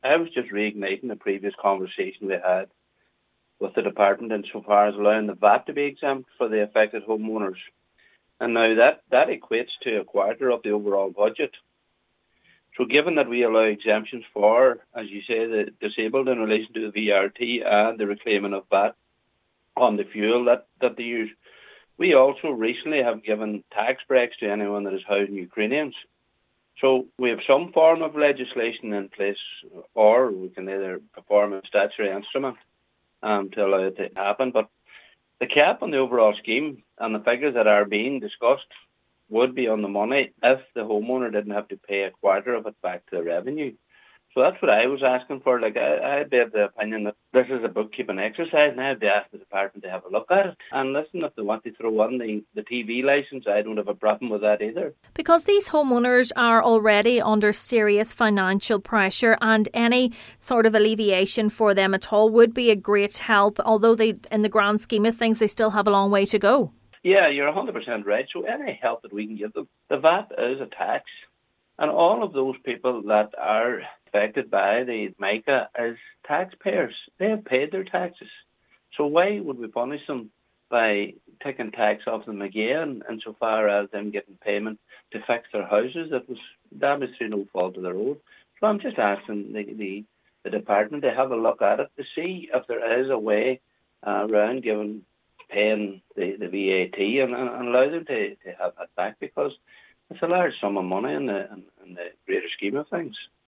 He says there is existing legislation that could be rolled out to defective block homeowners: